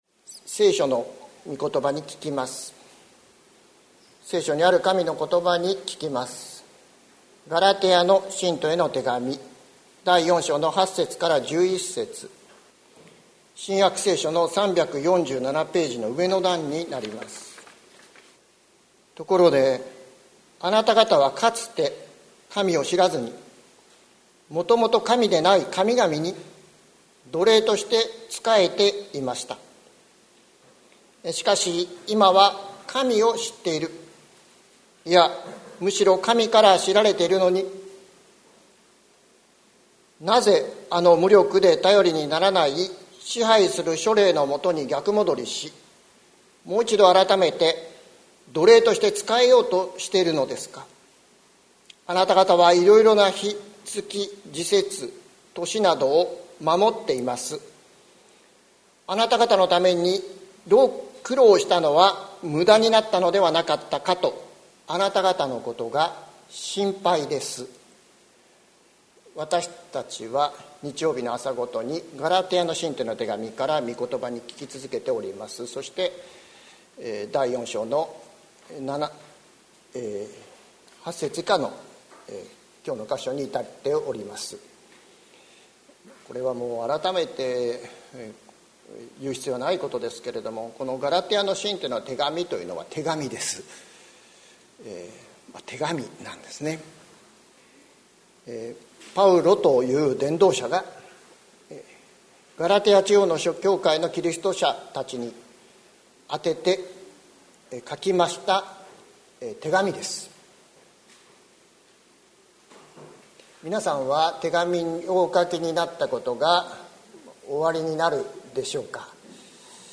2021年07月04日朝の礼拝「神の愛のなかで知られている」関キリスト教会
説教アーカイブ。